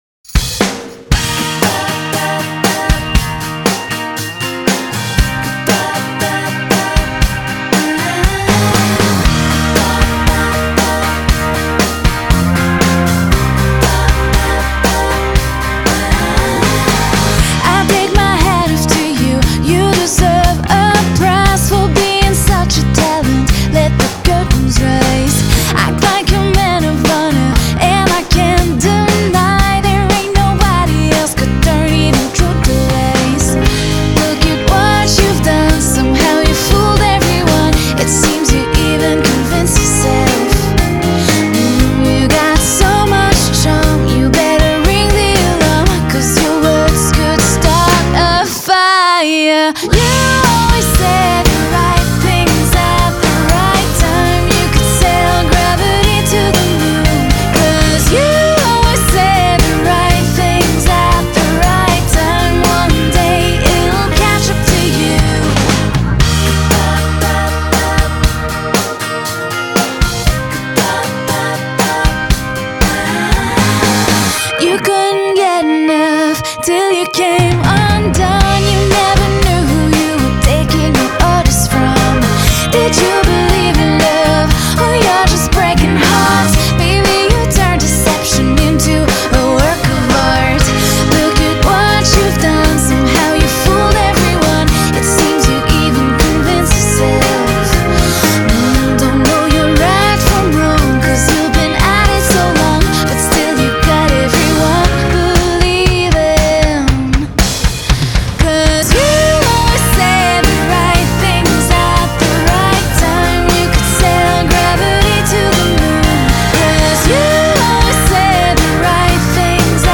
Genre.........................: Pop